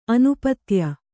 ♪ anupatya